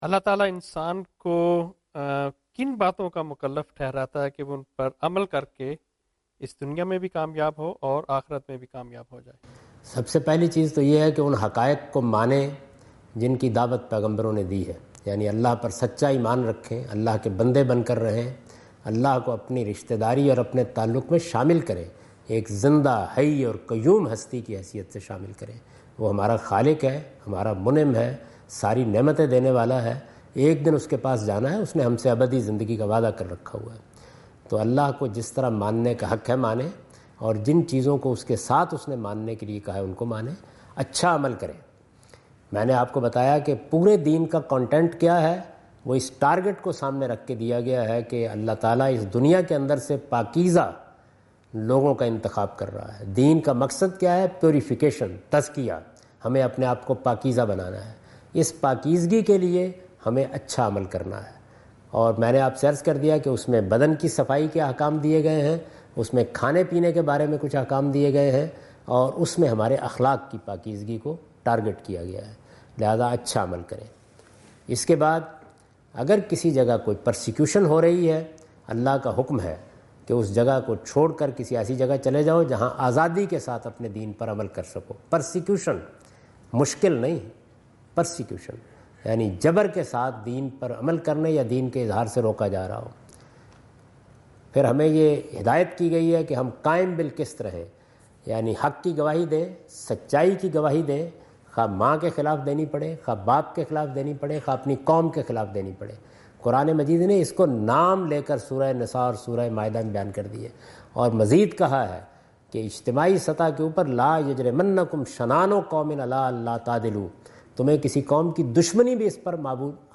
Javed Ahmad Ghamidi answer the question about "which duties does Allah impose on man?" in Macquarie Theatre, Macquarie University, Sydney Australia on 04th October 2015.
جاوید احمد غامدی اپنے دورہ آسٹریلیا کے دوران سڈنی میں میکوری یونیورسٹی میں "اللہ انسان کو کن چیزوں کا مکلف ٹھہراتا ہے" سے متعلق ایک سوال کا جواب دے رہے ہیں۔